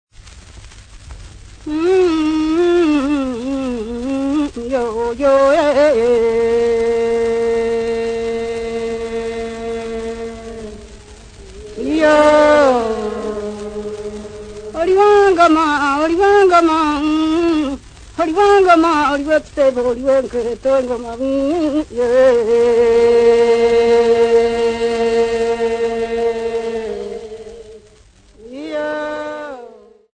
Dance music
Field recordings
sound recording-musical
Wedding song performed during procession of bride and groom
96000Hz 24Bit Stereo